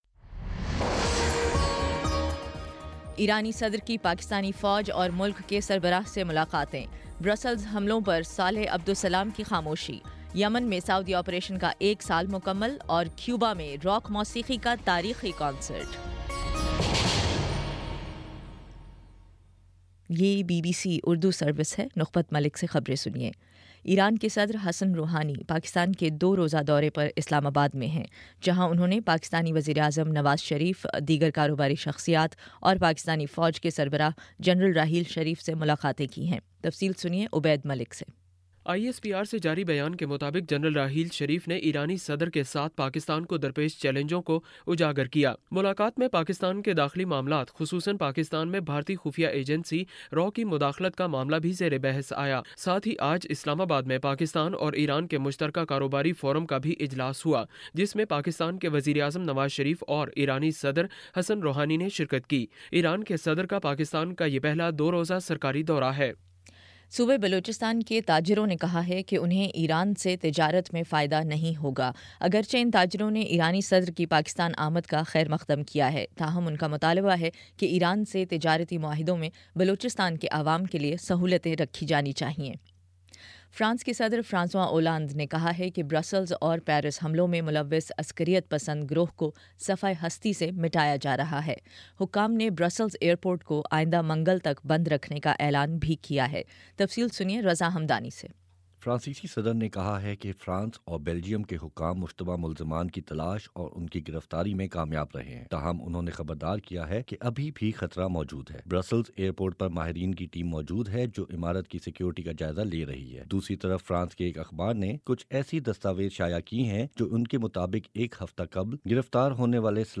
مارچ 26 : شام پانچ بجے کا نیوز بُلیٹن